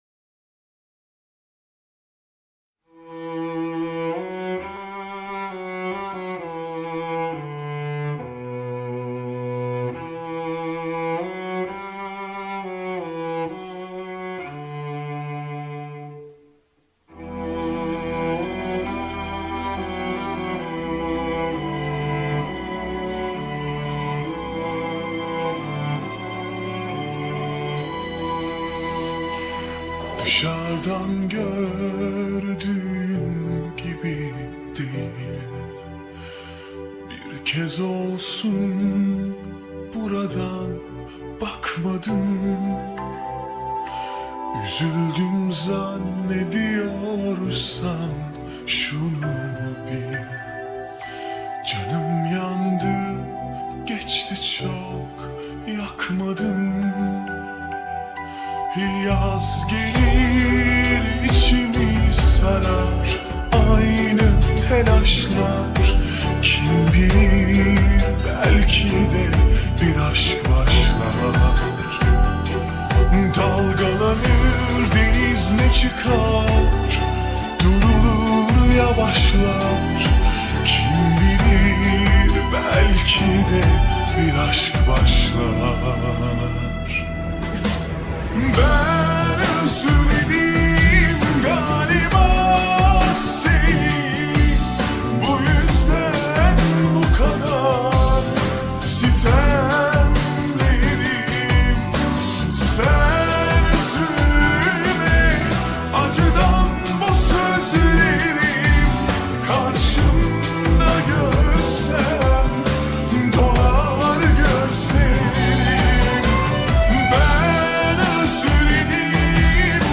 ورژن کاهش سرعت آهسته